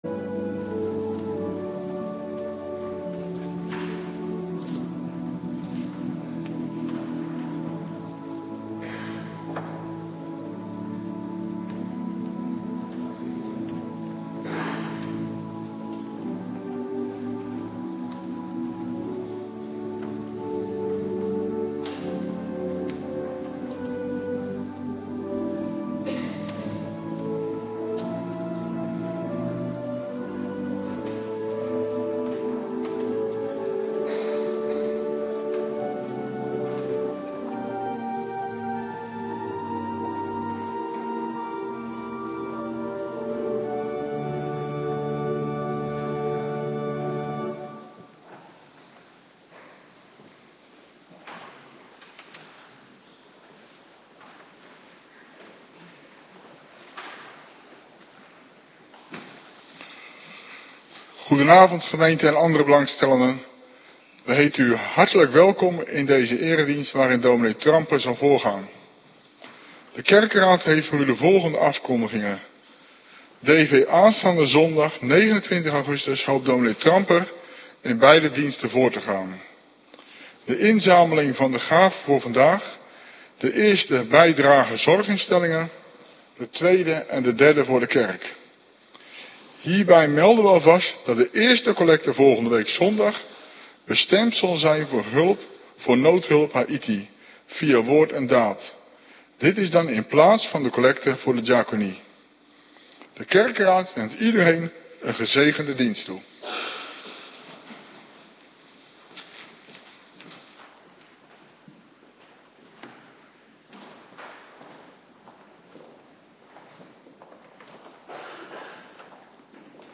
Avonddienst - Cluster A